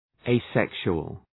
Προφορά
{eı’sekʃʋəl}